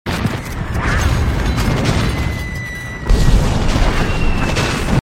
EXPLOSIONS POPPING AND WHIRING.mp3
Big distant explosions wiring in the field, different reverb tails coming out from everywhere.
.WAV .MP3 .OGG 0:00 / 0:05 Type Mp3 Duration 0:05 Size 864,12 KB Samplerate 44100 Hz Bitrate 256 kbps Channels Stereo Big distant explosions wiring in the field, different reverb tails coming out from everywhere.
explosions_popping_and_whiring_xof.ogg